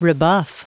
Prononciation du mot rebuff en anglais (fichier audio)
Prononciation du mot : rebuff